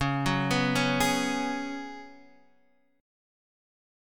Db7sus4 chord